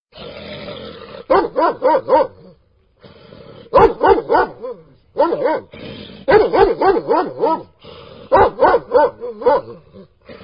جلوه های صوتی
دانلود صدای سگ از ساعد نیوز با لینک مستقیم و کیفیت بالا
برچسب: دانلود آهنگ های افکت صوتی انسان و موجودات زنده دانلود آلبوم صدای حیوانات از افکت صوتی انسان و موجودات زنده